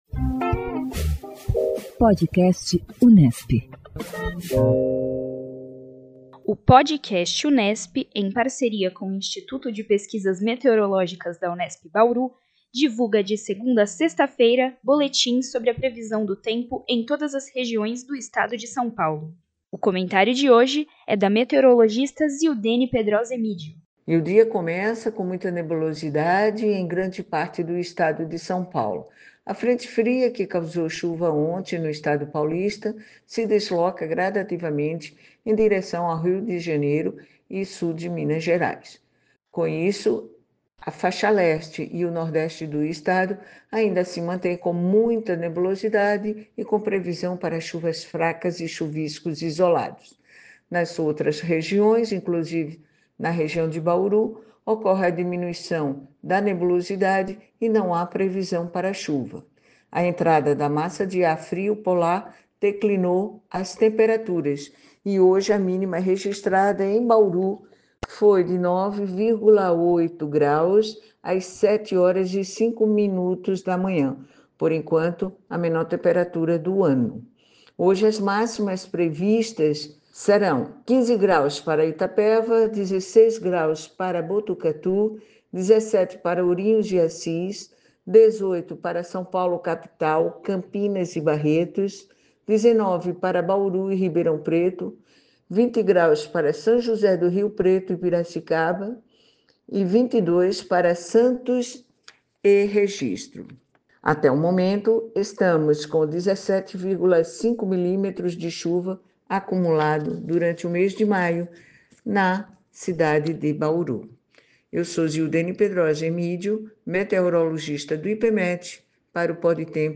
O Podcast Unesp, em parceria com o Instituto de Pesquisas Meteorológicas da Unesp, divulga diariamente boletins sobre a previsão do tempo em todas as regiões do Estado de São Paulo.